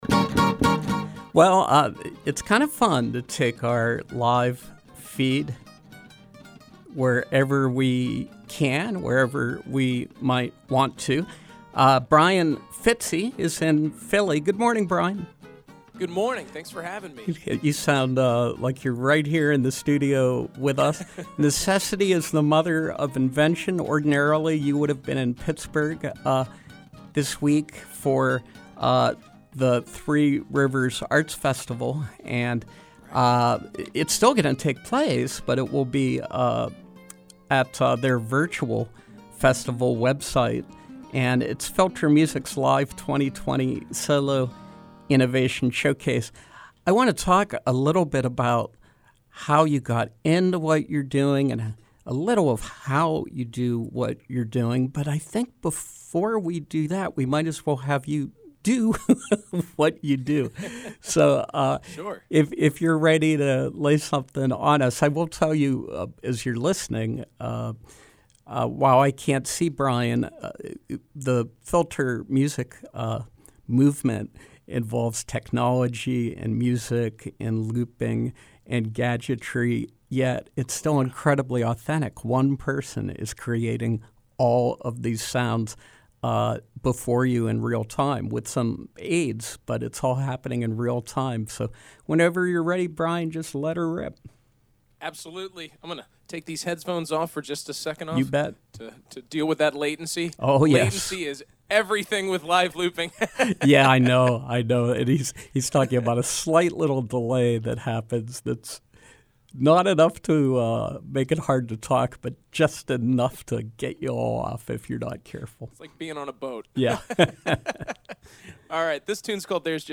remote interviews and performance previews